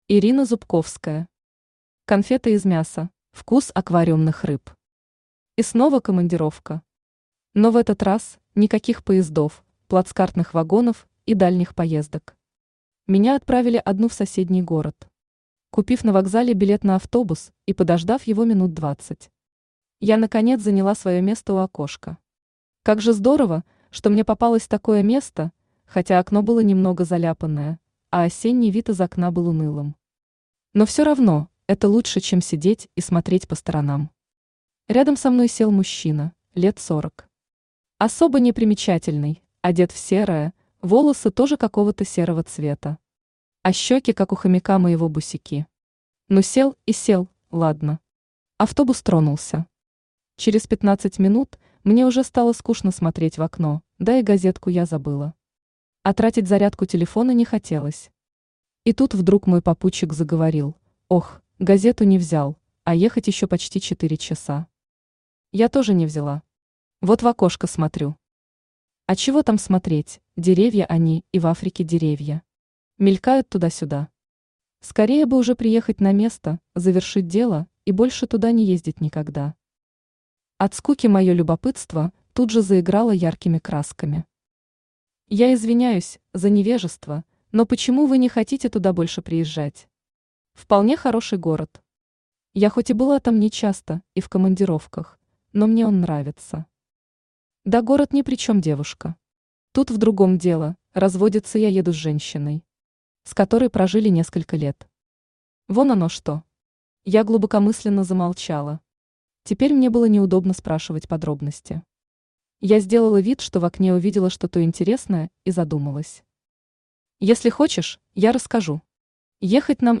Аудиокнига Конфеты из мяса | Библиотека аудиокниг
Aудиокнига Конфеты из мяса Автор Ирина Зубковская Читает аудиокнигу Авточтец ЛитРес.